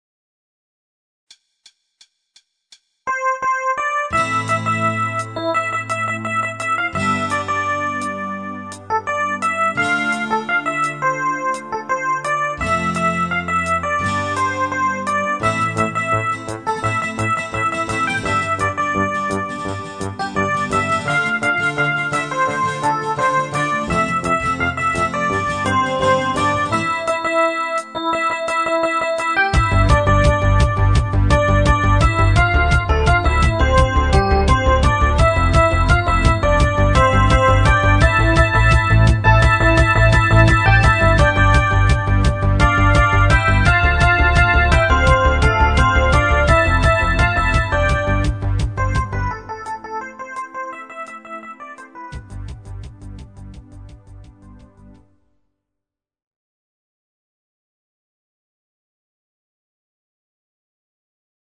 Genre(s): Deutschpop  Partyhits  |  Rhythmus-Style: Fastbeat